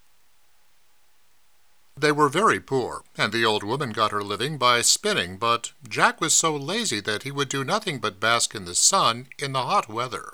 I’m using a CAD U37 mike run directly through a USB port to a Win10 laptop.
This is a standard narration, the start of a three-book deal with my current author.
The voice is very hard and crisp.
You have “essing,” too. Harsh SS sounds.